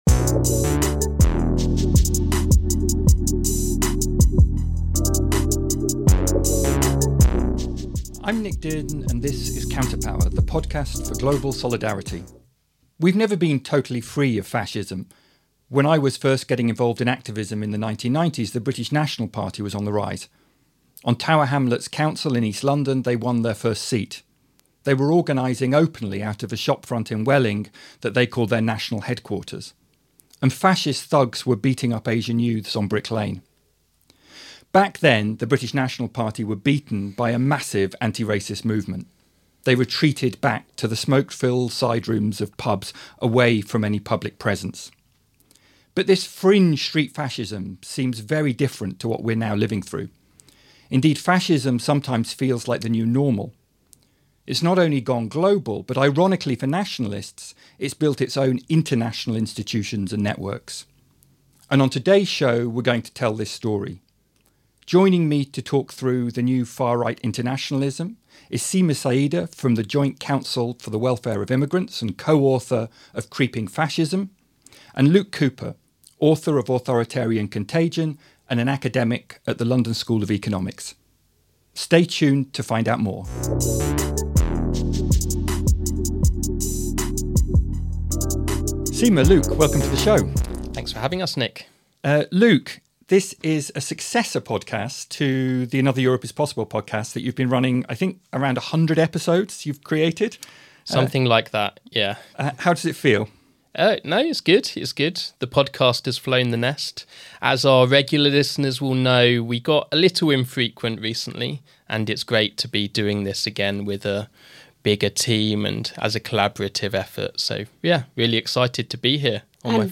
Their conversation explores how the far right has built international networks, institutions, and narratives that now feel disturbingly normal. They dig into why “fascism” remains a contested term on the left, what’s genuinely new about the current moment, and what can still be done to resist it.